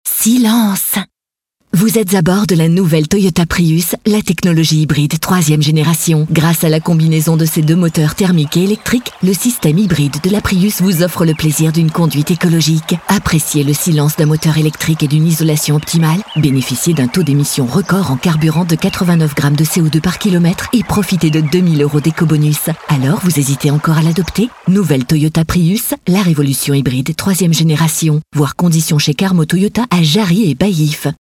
Nous pouvons créer pour vous les textes de vos spots publicitaires et réalisé ensuite l’enregistrement et le montage sur musique.